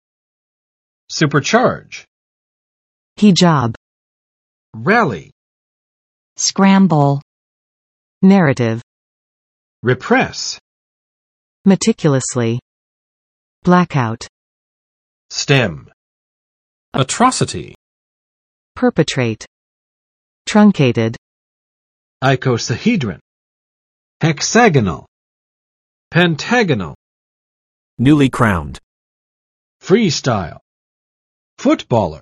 [͵supɚˋtʃɑrdʒ] or [ˋsupɚ͵tʃɑrdʒ] v. 对……使用增压器; increase or raise